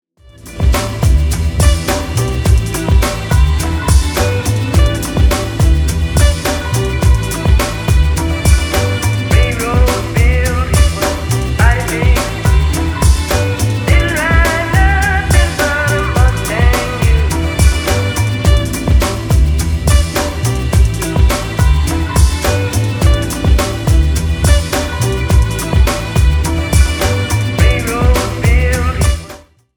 • Качество: 320, Stereo
deep house
Electronic
спокойные
красивая мелодия
Downtempo
Breaks
Интересная спокойная мелодия.